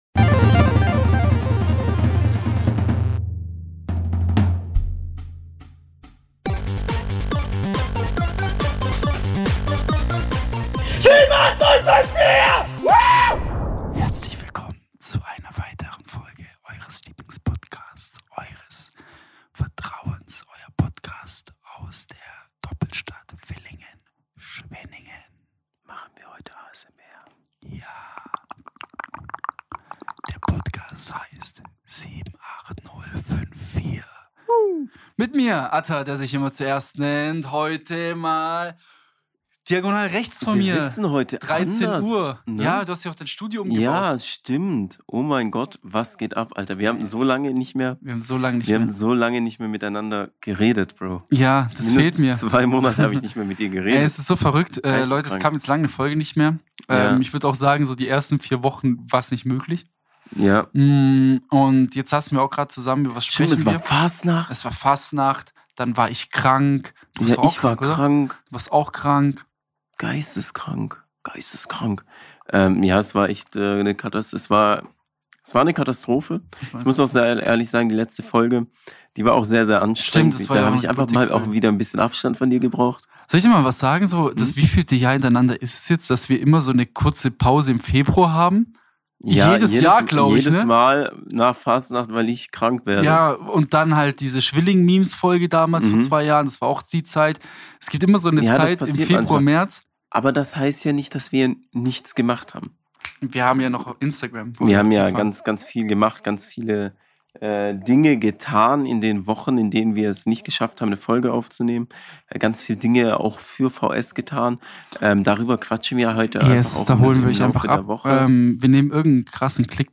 LIVE Ausschnitte von der Blockparty‘24 für den optimalen ADHS Kick.